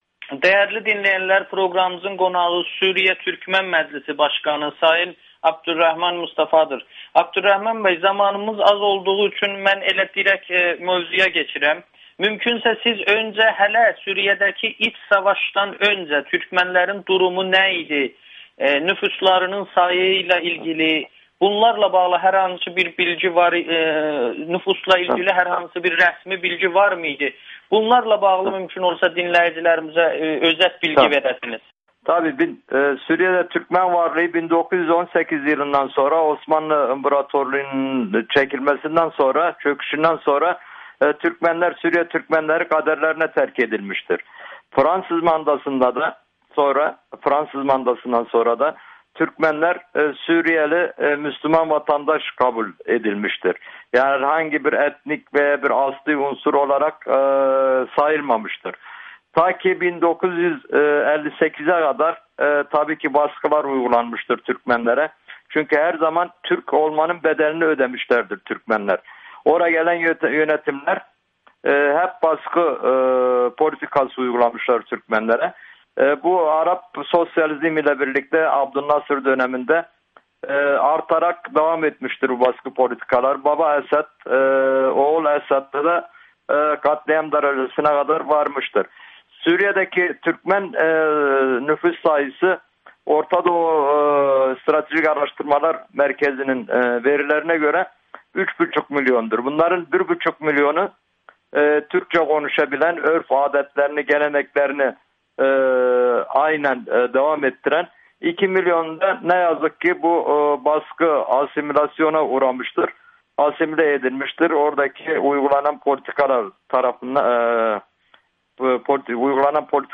Suriya Türkmən Məclisi Başqanı: Torpaqlarımızda gözləri var [Audio-Müsahibə]